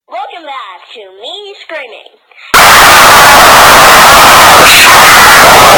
Play Earrape Welcome back to me screaming soundboard button | Soundboardly
earrape-welcome-back-to-me-screaming.mp3